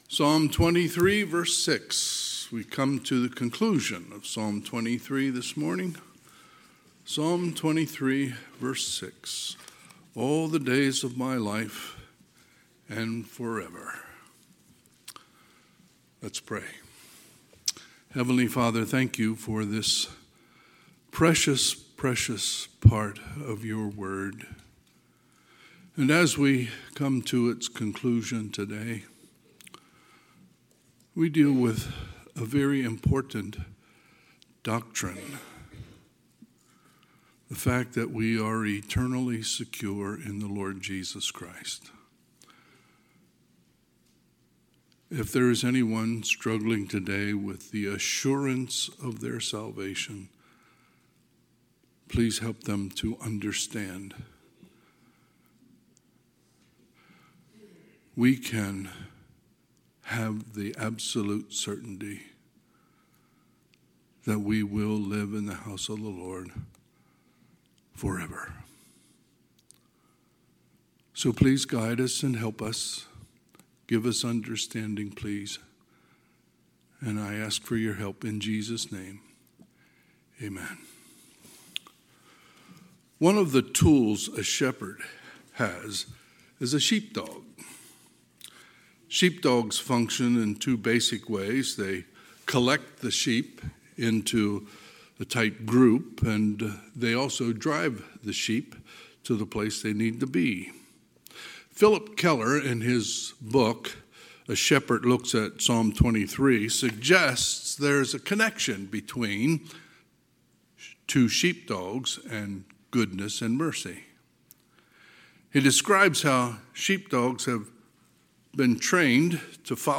Sunday, November 23, 2025 – Sunday AM
Sermons